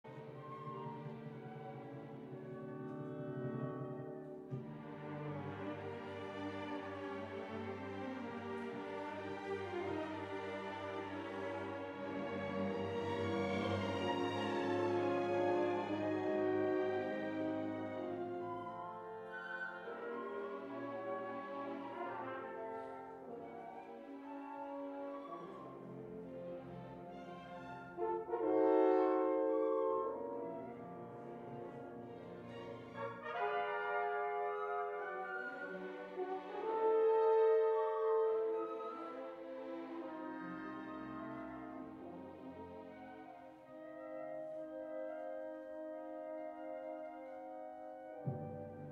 The oboe adds another mysterious, unsure character to the scene, but sounds nevertheless very passionate.
In terms of instrumentation Strauss chose, as it was usual for the Romantic period, a big orchestra, the instruments were the following: 3 flutes, English horn, 2 clarinets, 2 bassoons, contrabassoon, 4 horns, 3 trumpets, 3 trombones tuba, harp, timpani, triangle, cymbal, chime and strings. 10